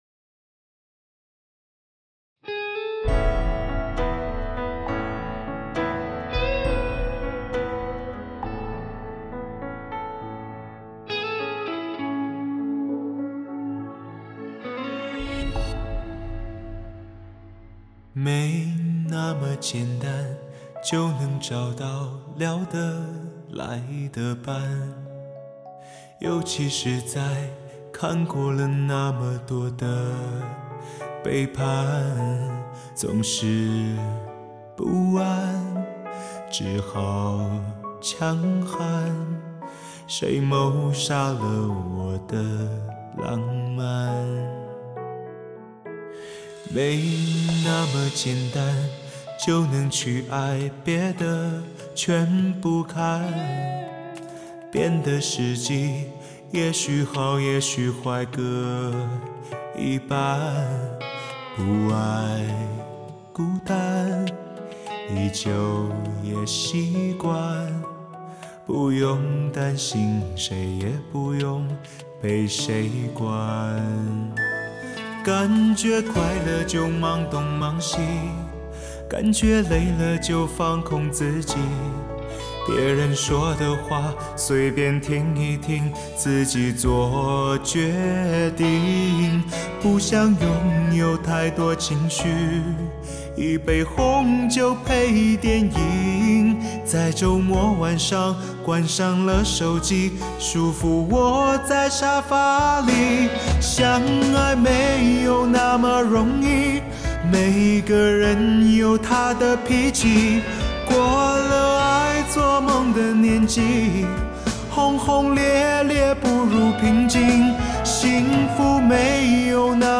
人声格外暖厚，磁性动人，感情到位，发烧极品及制作令人耳不暇接，无论试音及欣赏两皆相宜。